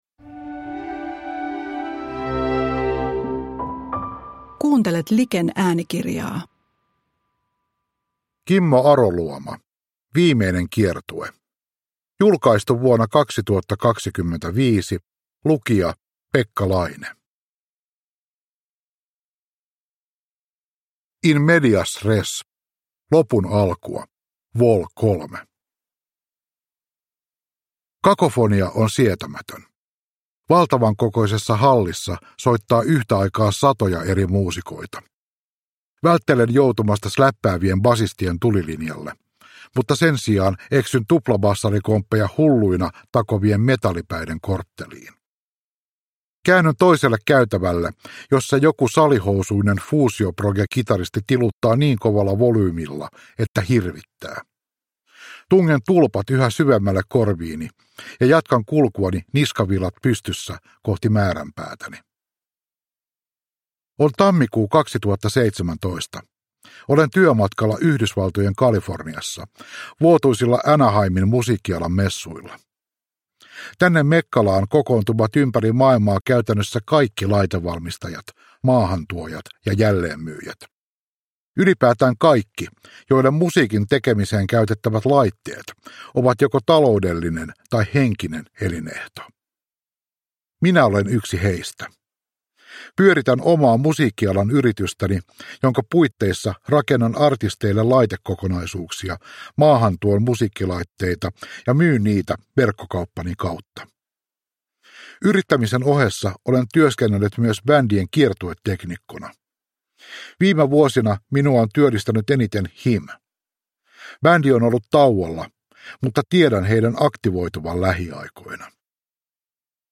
Viimeinen kiertue – Ljudbok